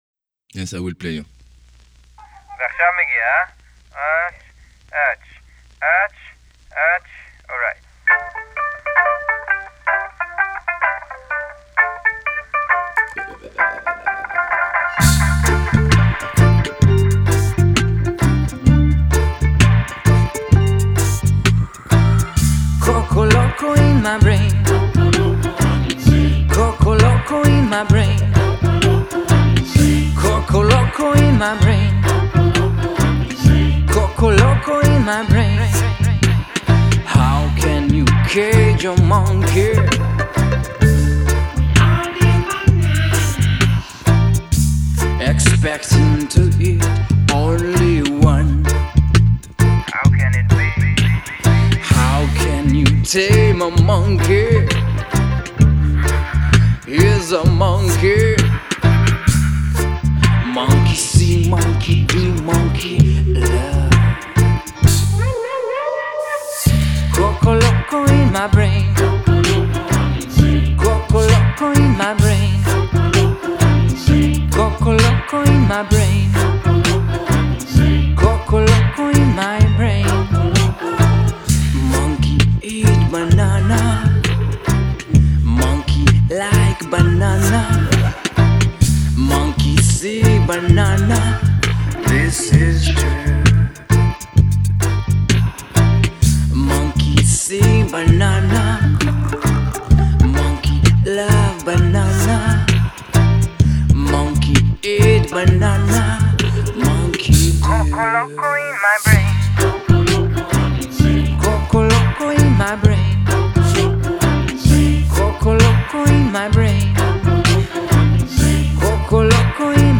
Electro – Ethnic – Reggae group
Genre: World